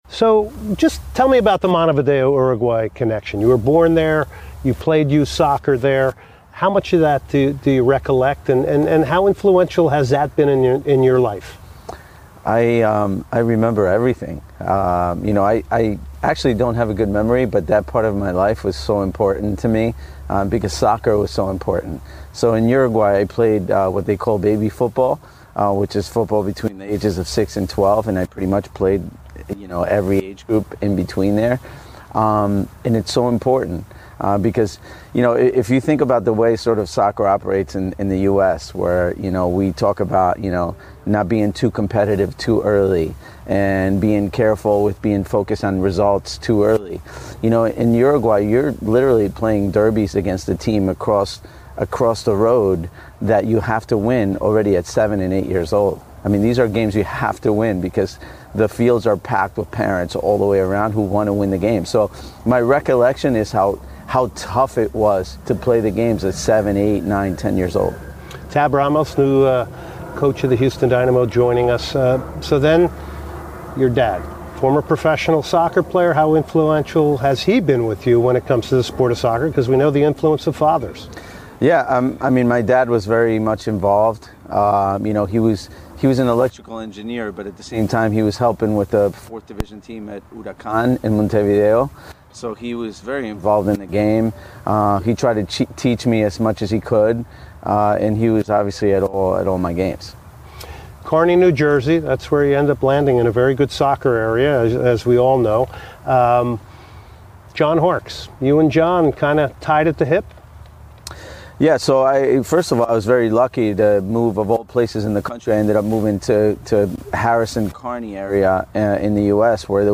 11/19/2019 Houston Dynamo Head Coach Tab Ramos Full Interview